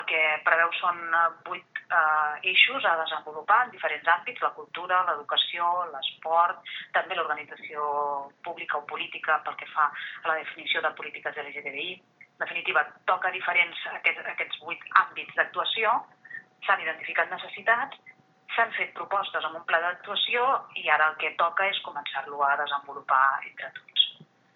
Paral·lelament, la vicepresidenta i consellera comarcal d’Igualtat, Sílvia Romero, ha explicat que el pla estratègic s’ha fet de manera participativa i “persegueix promoure aquesta igualtat d’accés i d’oportunitats del col·lectiu LGTBI a la comarca.”